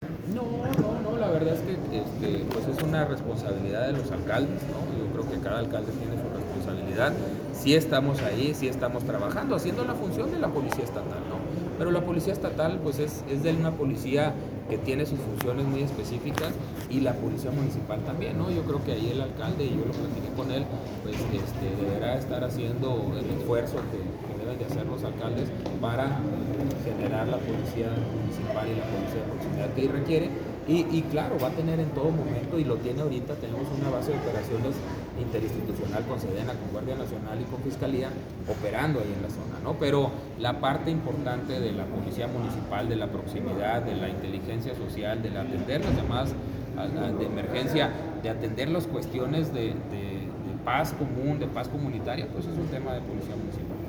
Audio. Secretario de Seguridad Pública del Estado (SSPE), Gilberto Loya.
Loya-sobre-municipales-en-Villa-Coronado.mp3